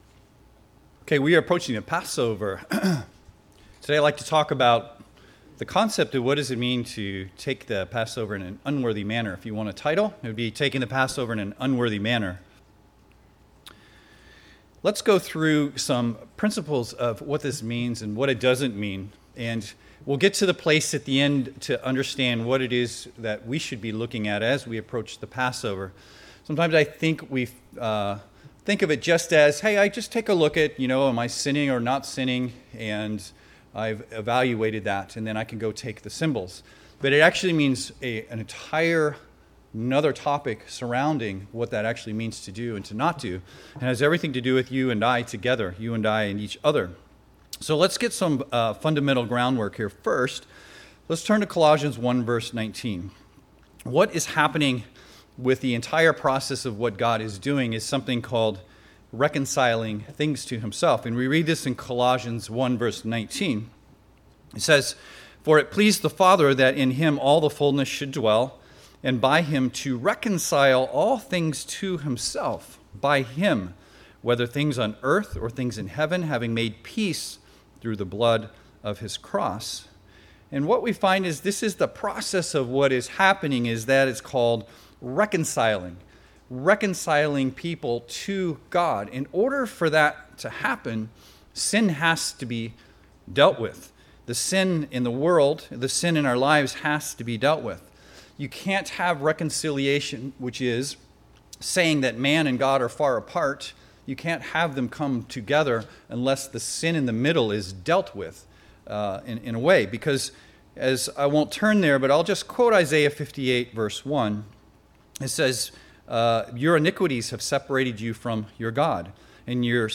This sermon also covers how Christ's sacrifice is just as concerned with healing the emotional aspects behind sin we struggle with as it is with dealing with the sin.